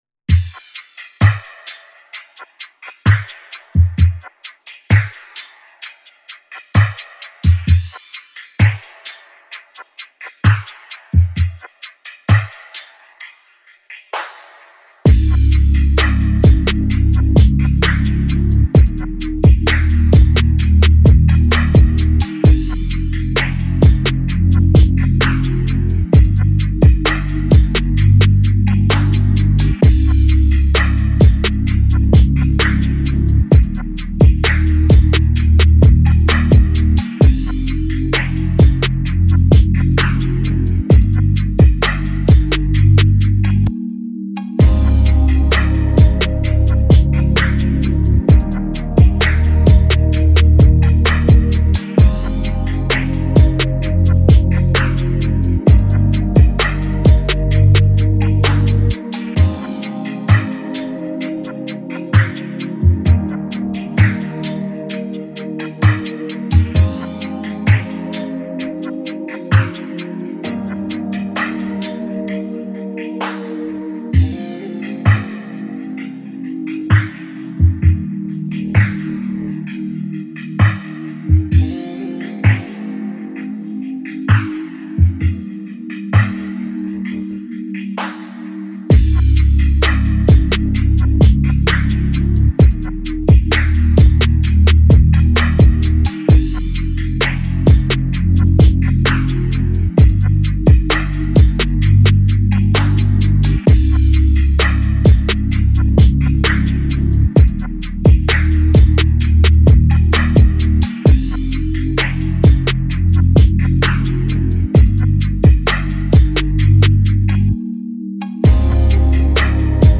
official instrumental
2024 in Hip-Hop Instrumentals